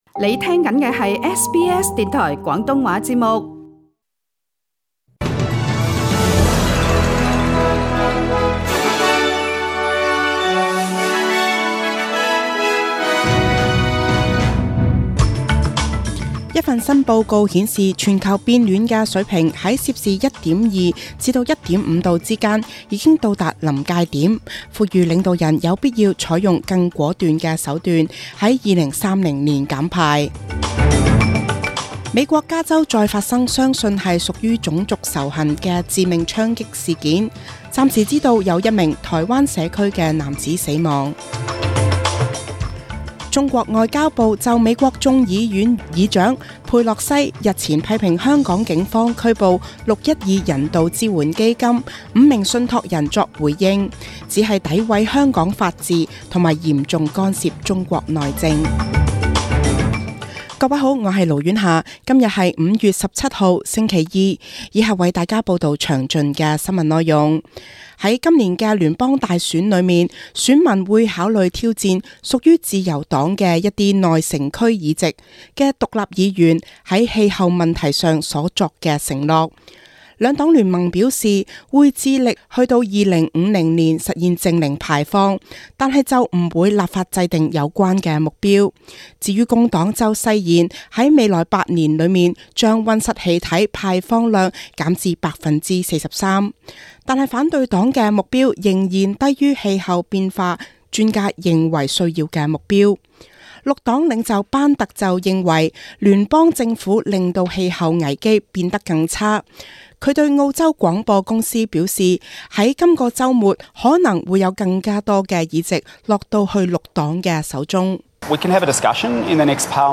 SBS 中文新聞（5月17日）
SBS 廣東話節目中文新聞 Source: SBS Cantonese